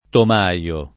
tomaia [ tom #L a ]